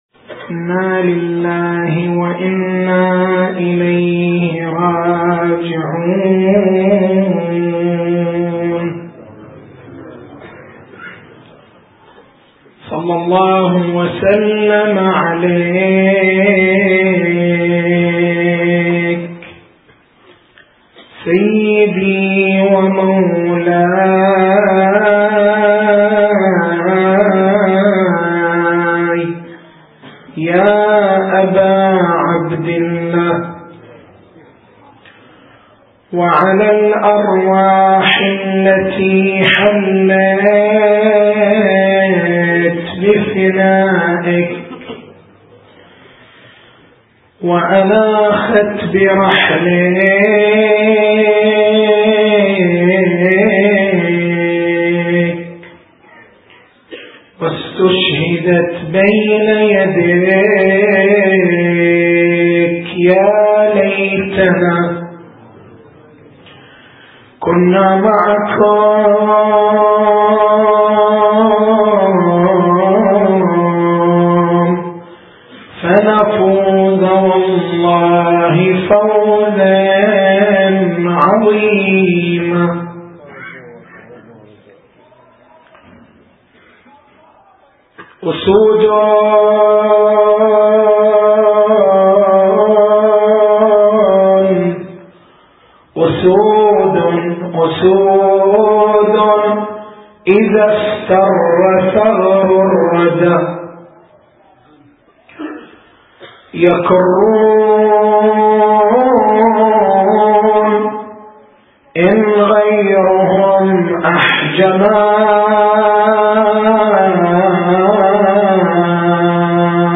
تاريخ المحاضرة: 06/01/1429 نقاط البحث: أهمية منصب المرجعية الإفتاء القضاء الولاية مرجعية عقائدية، أم فقهية فقط؟